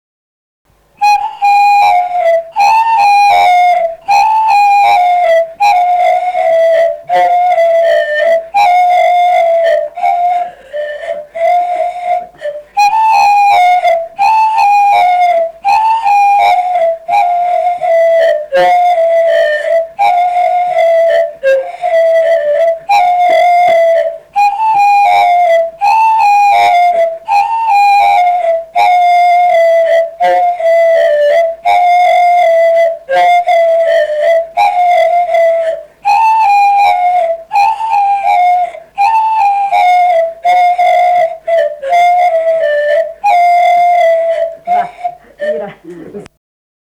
sutartinė
Biržai
instrumentinis
skudučiai